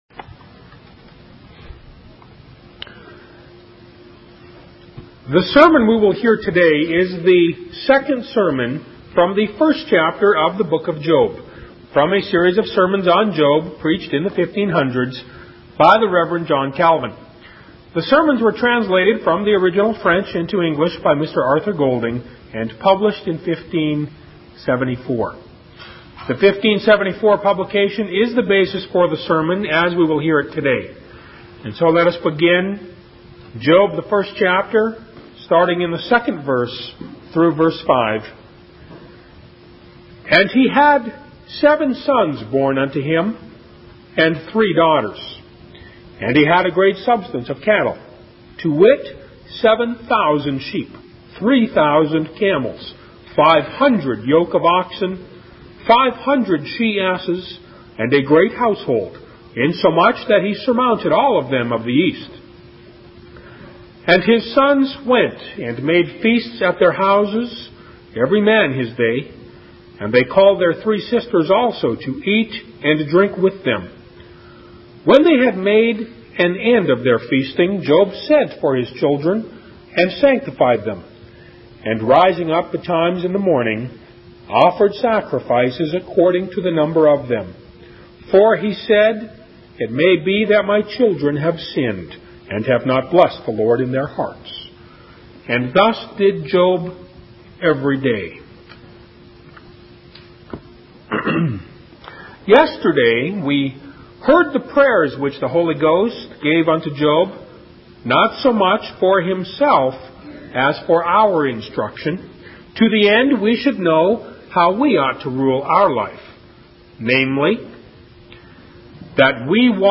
In this sermon, the Reverend John Calvin focuses on the story of Job from the book of Job in the Bible.